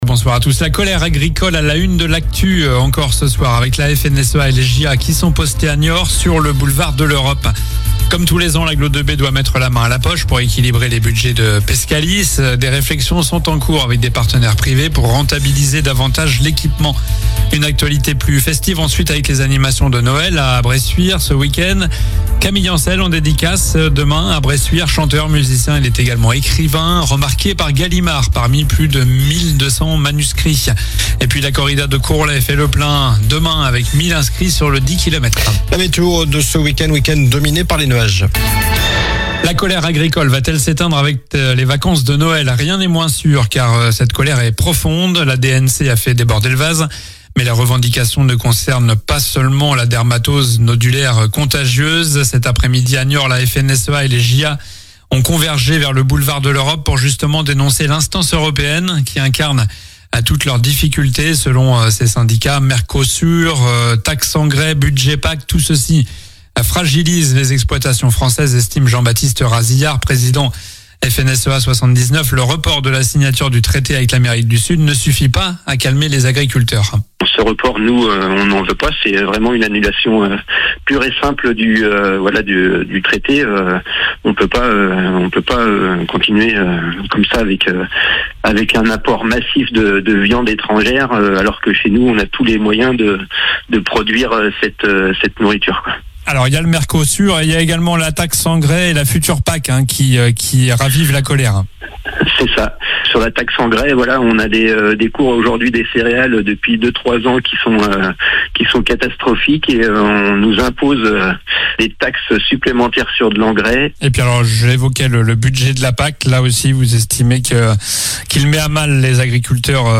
Journal du vendredi 19 décembre (soir)